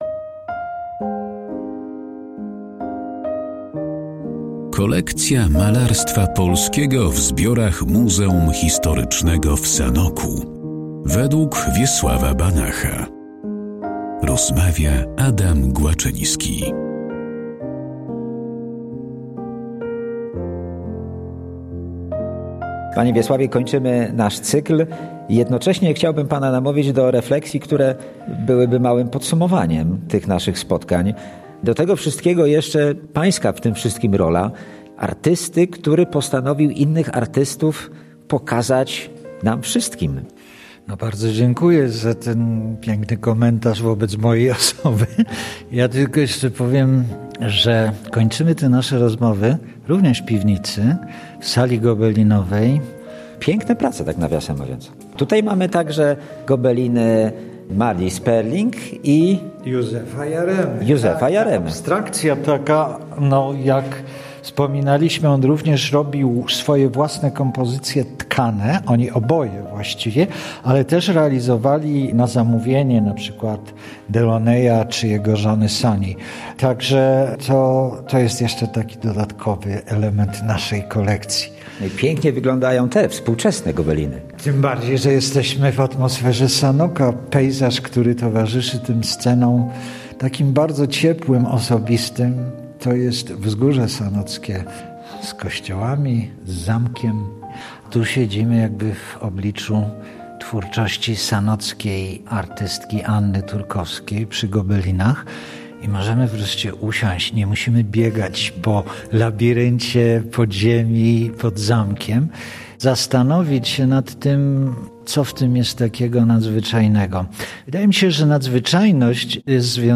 To podsumowanie rozmów, które zostały zrealizowane w Galerii im. Marii i Franciszka Prochasków.